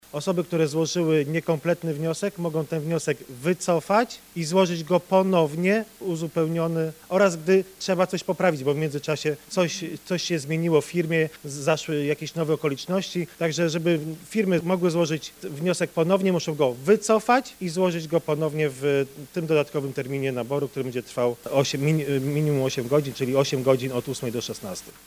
Taka informację przekazała dziś na briefingu marszałek Elżbieta Polak.